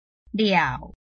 臺灣客語拼音學習網-客語聽讀拼-南四縣腔-開尾韻
拼音查詢：【南四縣腔】liau ~請點選不同聲調拼音聽聽看!(例字漢字部分屬參考性質)